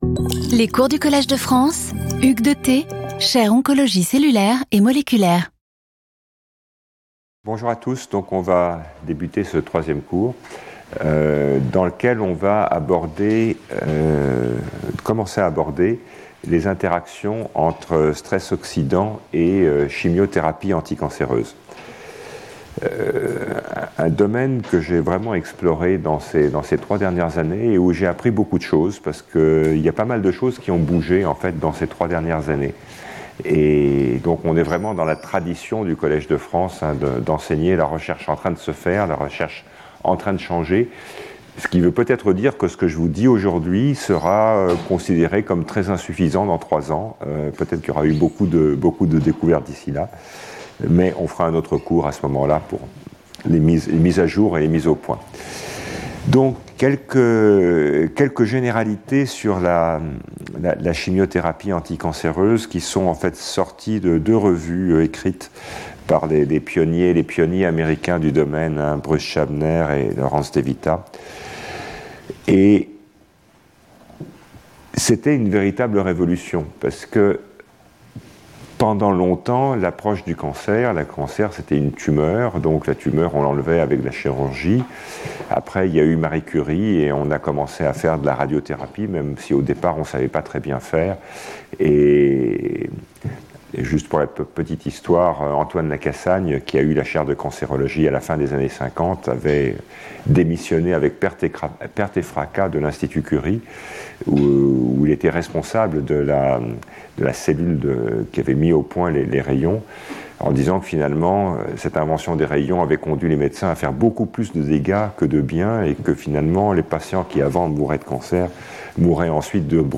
Hugues de Thé Professor at the Collège de France
Lecture